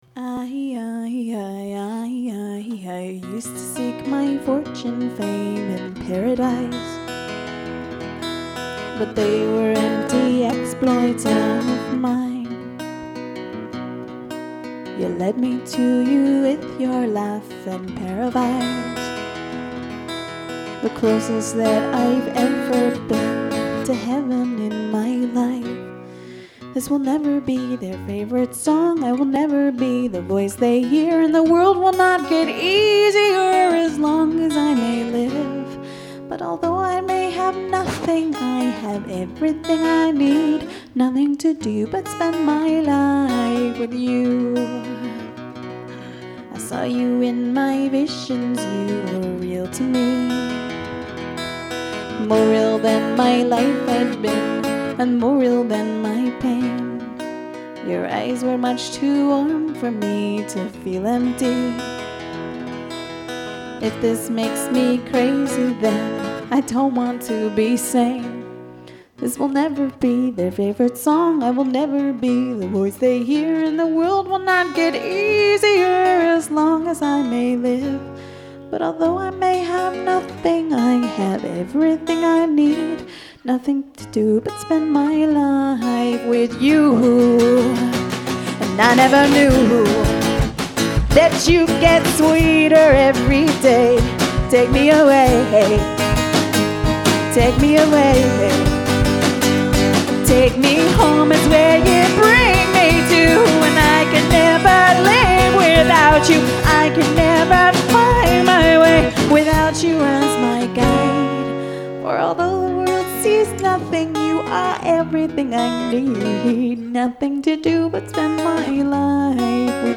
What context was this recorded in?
Live @ the Matrix 5/29/04: